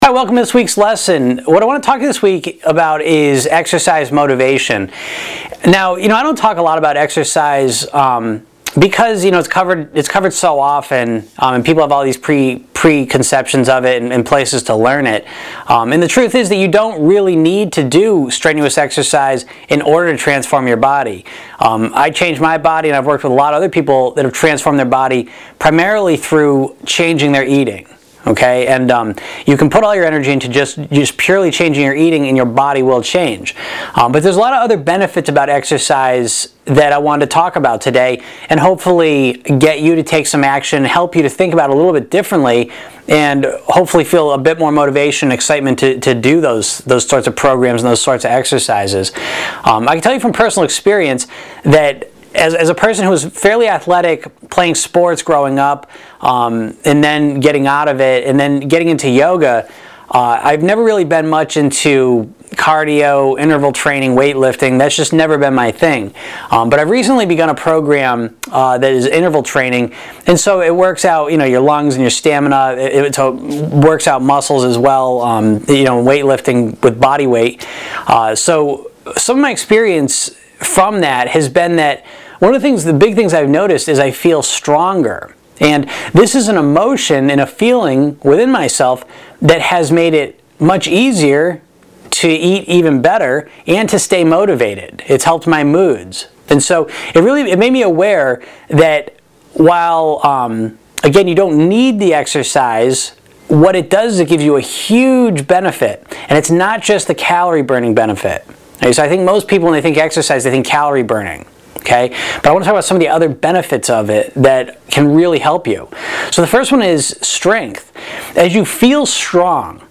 Lesson #44